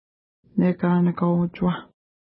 ID: 51 Longitude: -58.9820 Latitude: 52.8612 Pronunciation: neka:nəka:w-utʃuwa: Translation: Sandy Shores Mountains Feature: mountains Explanation: Named in reference to nearby lake Nekanakau (no 66).